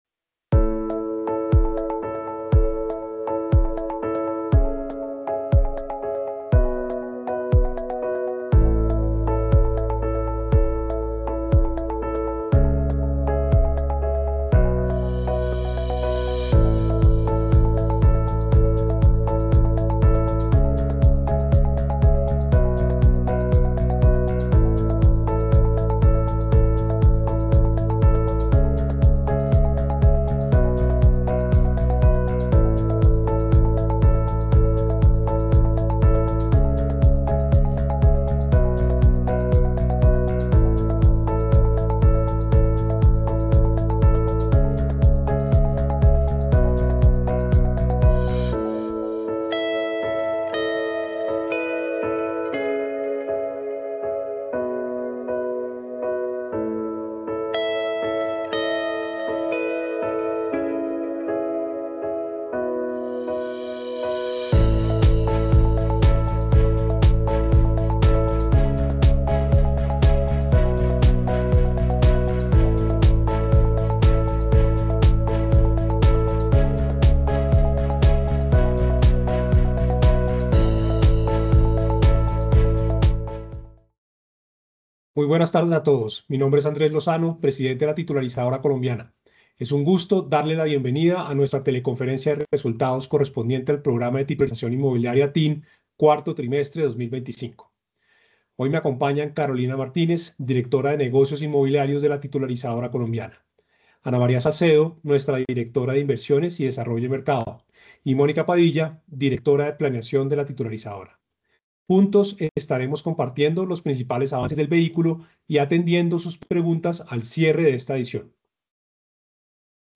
Teleconferencia_de_Resultados_TIN_Cuarto_Trimestre_2025.mp3